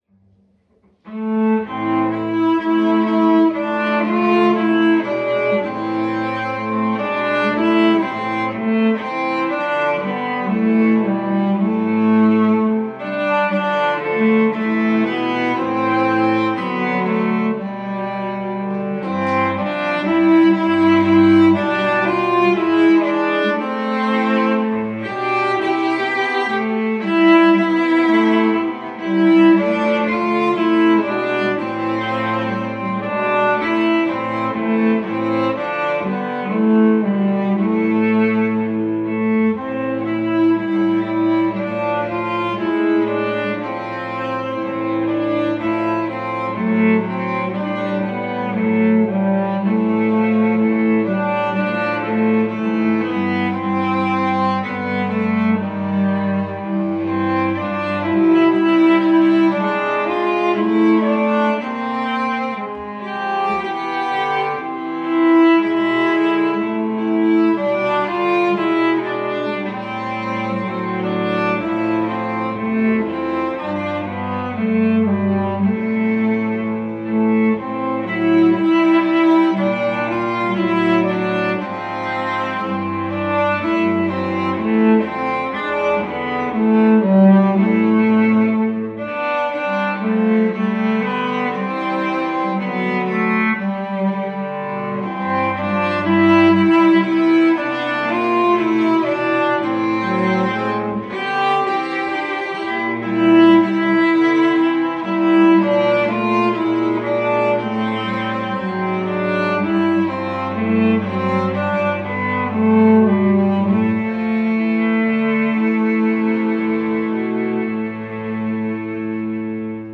Cello Recital - Oh Come, Oh Come Emmanuel
I performed a cello trio in my cello teacher's studio recital.
Since this was done in isolation, I could not play with others, so by the magic of recording I played all the parts. I made an arrangement of the song Oh Come, Oh Come Emmanuel for three cellos. The melody is the stays the same each time, but the harmony has interesting variation.